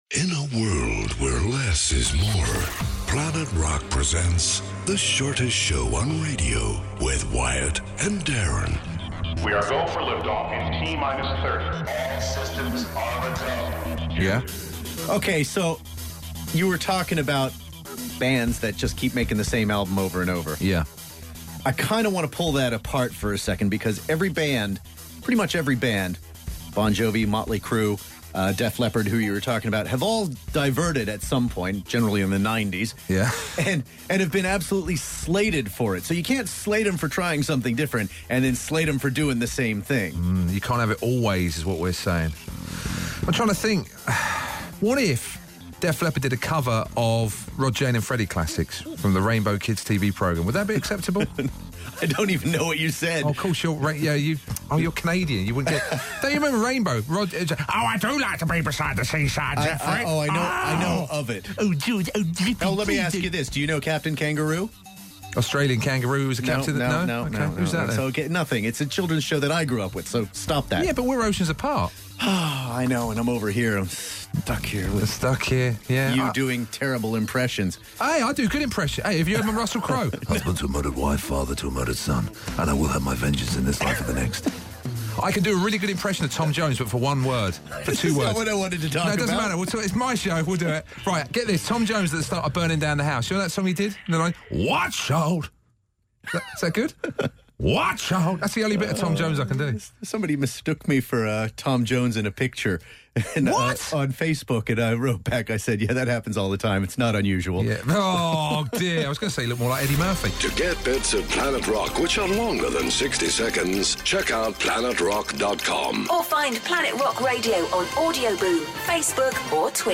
Impressions.